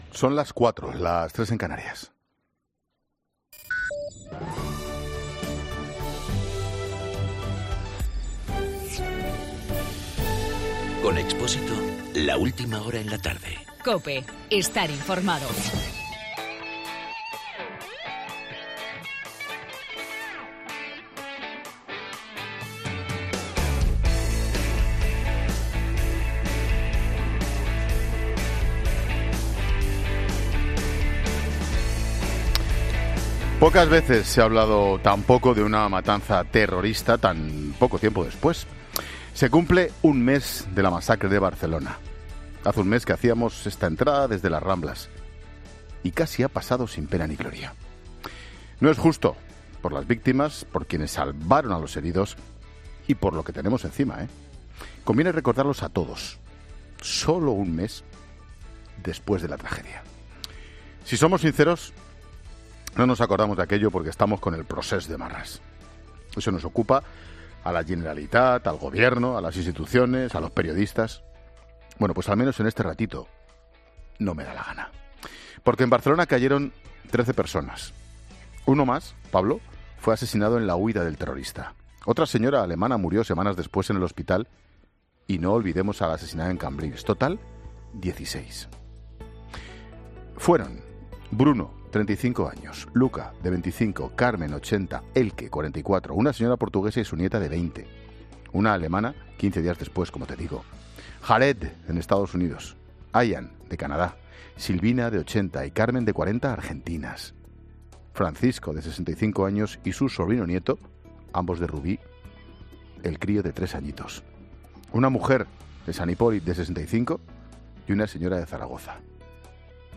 Monólogo de Expósito
Ángel Expósito comenta en su monólogo de las cuatro la capacidad del procés para hacer borrar de la mente los atentados de Barcelona. Tan solo un mes después de que el terror inundase las calles de la Rambla y Cambril, poco se habla de los atentados.